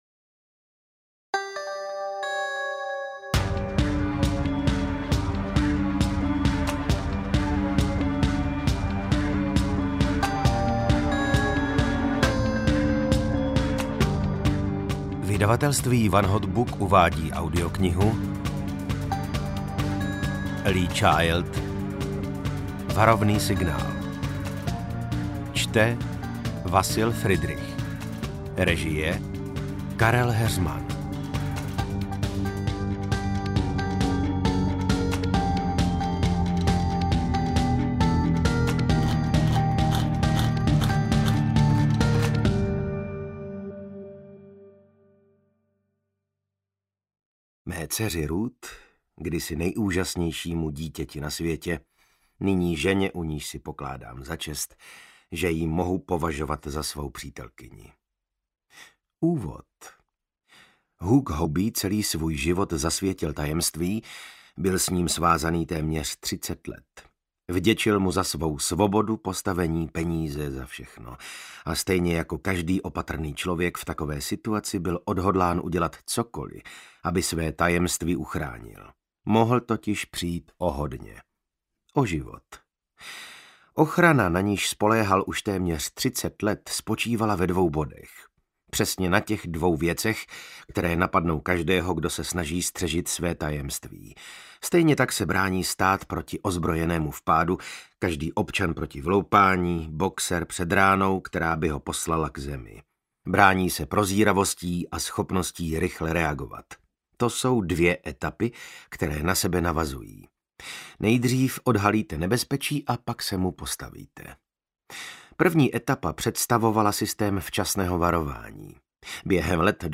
Interpret:  Vasil Fridrich
AudioKniha ke stažení, 43 x mp3, délka 16 hod. 23 min., velikost 899,6 MB, česky